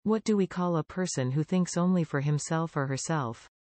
You will hear a question.